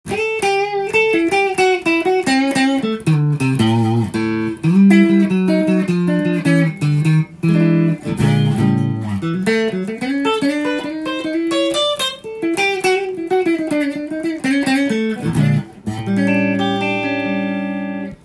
試しに弾いてみました
クリーントーンが磁力の弱った古臭いヴィンテージの音がします。
音のクオリティーが最高です！